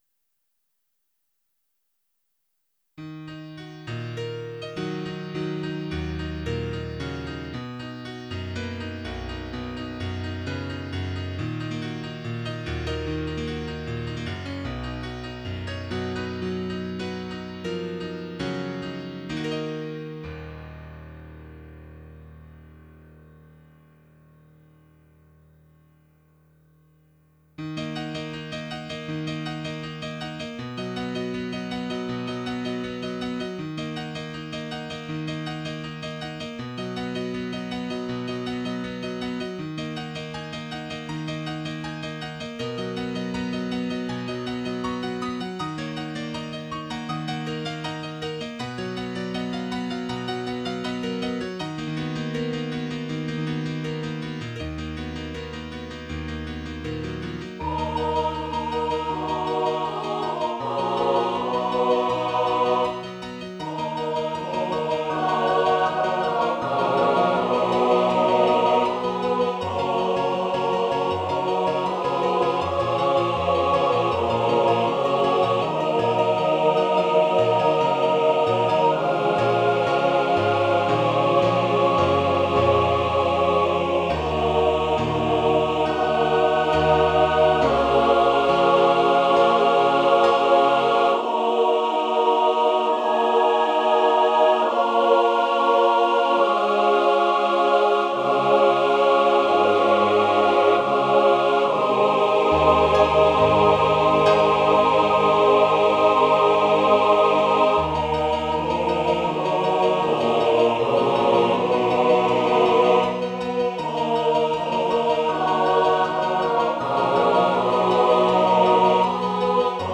The Accompanist (SATB
Piano, Choral, A Capella